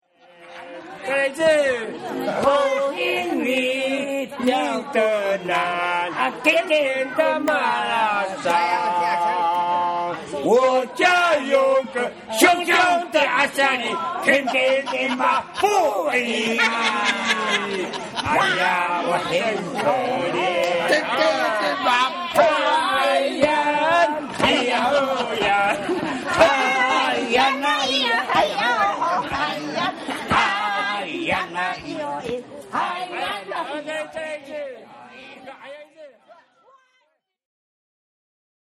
for example, on a christmas day gathering at the catholic church courtyard, elderly men and women sat at separate tables nearby each other; the tables of the middle aged women and men were on separate ends of the courtyard, with a single table for all of the youth undivided by gender between them. after meals or in periods when the elders will give speeches and the constituent groups–all the men, all the women, youth, middle aged people, residential divisions–performances, the young men will break down the tables and everyone move the chairs into a large circle
those of us watching admired the performance, particularly the contrast between the earnest quality of the dance line and the woman dancing with akadama sweet wine boxes as improvised props. the women danced, in part, to convince someone (perhaps even of their own group), to buy a bottle or two more. at the same time it was a taunt of sorts to the middle aged men, urging the men to get up and perform. the men responded with their own song, which made fun of quarrelsome old couples:
the men performed the song to the amusement of the elderly men and women present; and both groups joined in, even though it made fun of members of their group (by name). that the first man targeted by the joke danced and sang with the middle aged men as they performed added to the self-deprecatory quality. linking the song with a traditional dance piece associated with adolescent boys ensured that everyone would laugh and sing along